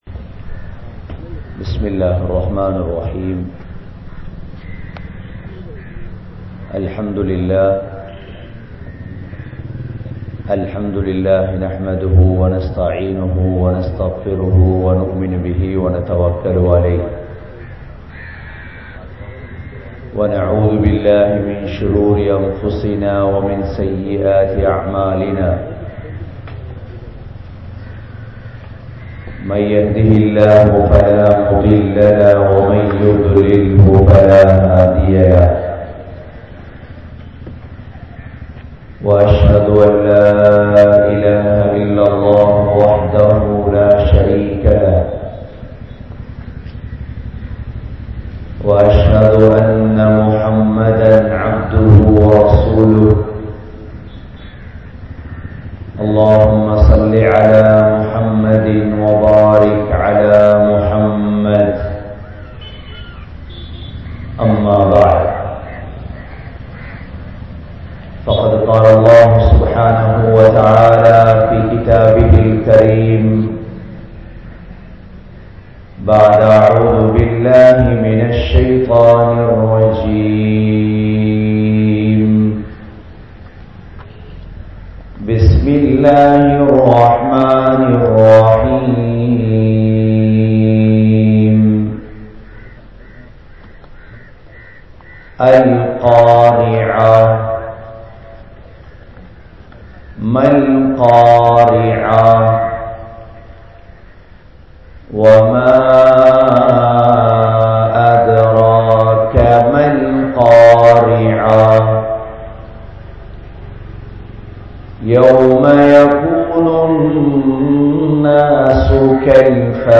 Puththi Saali Yaar?(புத்திசாலி யார்?) | Audio Bayans | All Ceylon Muslim Youth Community | Addalaichenai